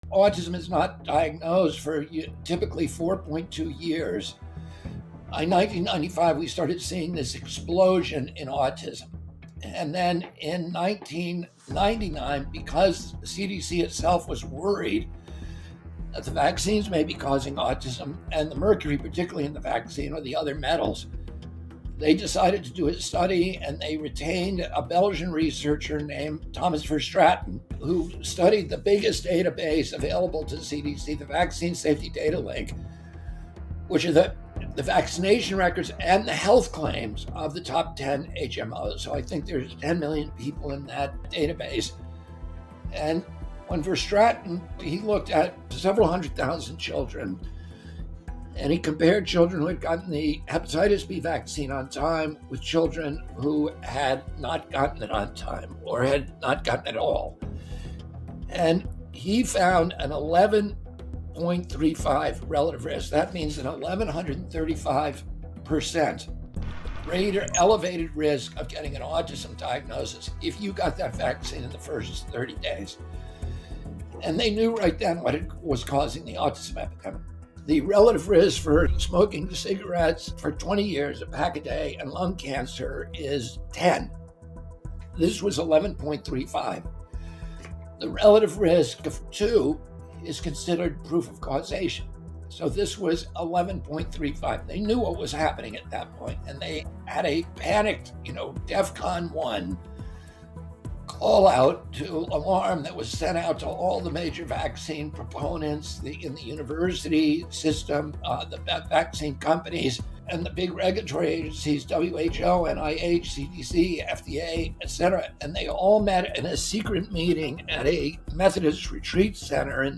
In this 3-minute video, RFK, Jr. describes how the CDC committed scientific fraud and attempted to bury the data when they discovered a link between the Hepatitis B vaccine and autism. (Excerpted from Episode 3 of REMEDY)